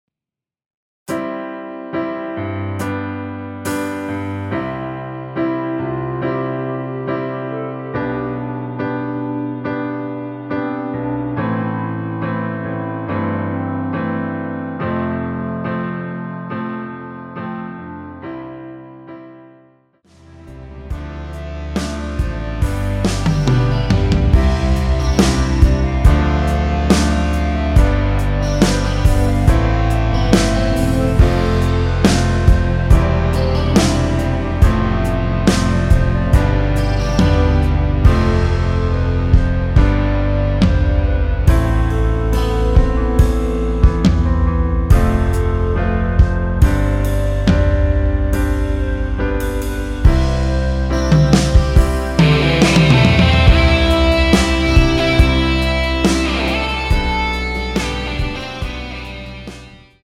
노래하기 편하게 전주 1마디 만들어 놓았습니다.(미리듣기 확인)
원키에서(-2)내린 멜로디 포함된 MR입니다.
앞부분30초, 뒷부분30초씩 편집해서 올려 드리고 있습니다.
중간에 음이 끈어지고 다시 나오는 이유는